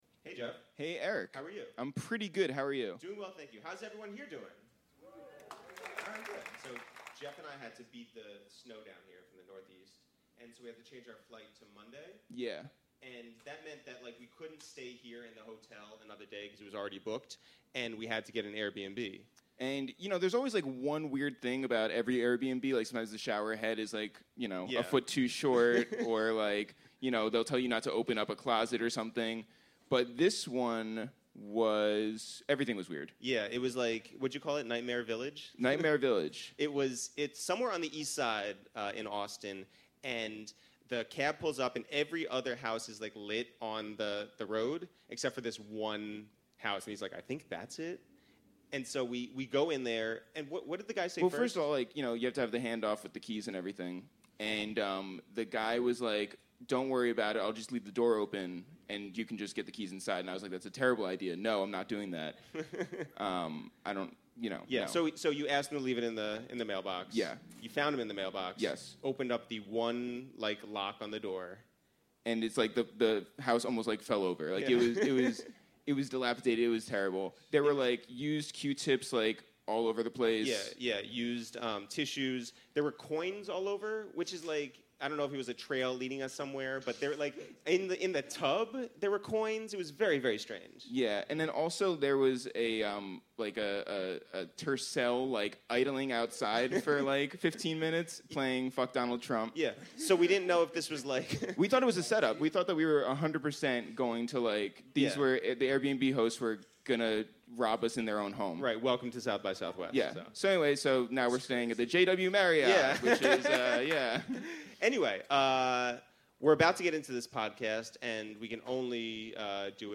#111: Live At SXSW With 24hrs And Danny Brown